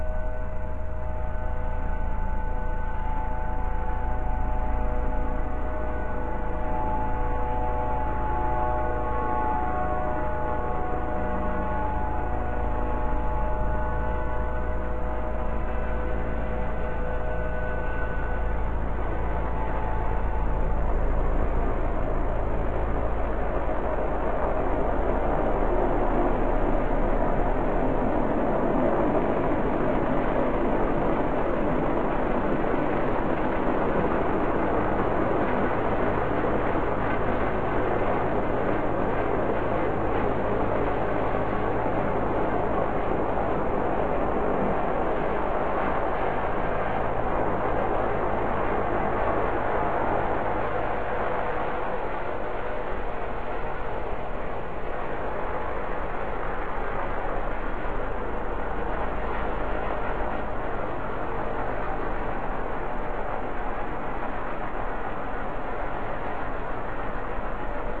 2020 WILTON ENVIRONMENTAL NOISE